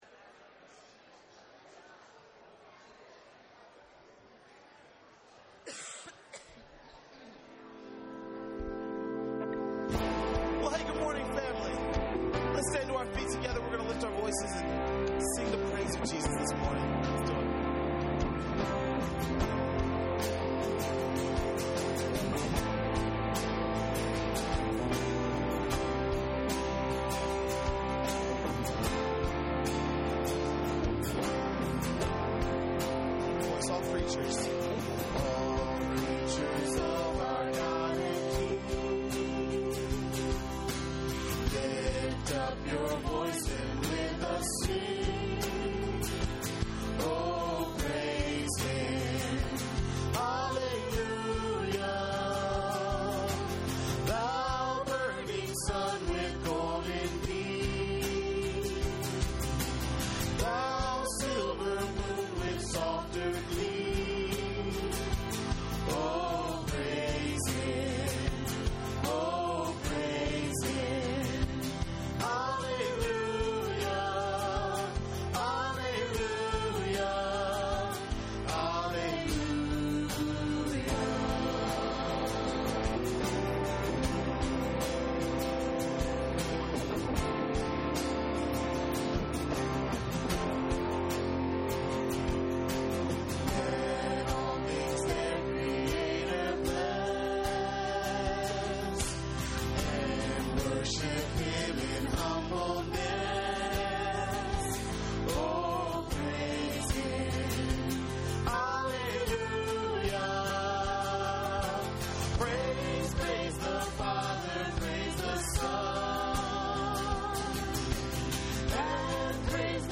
" Ephesians " Sermon Notes